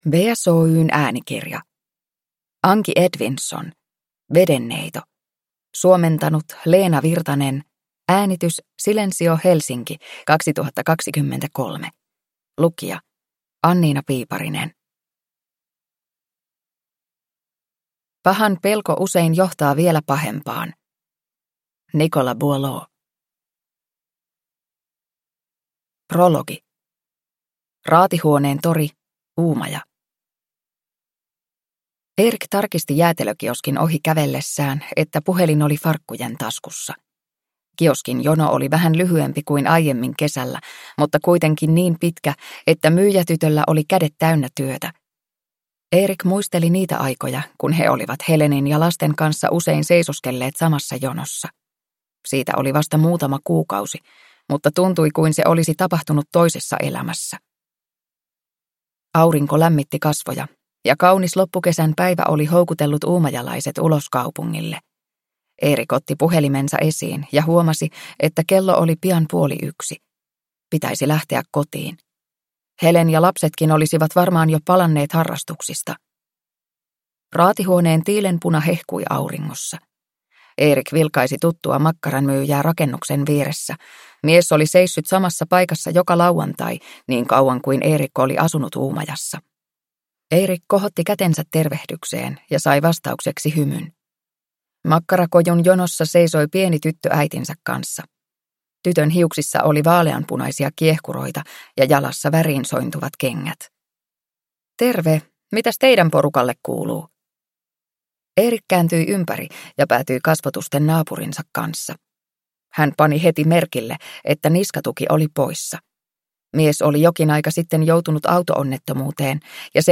Vedenneito – Ljudbok – Laddas ner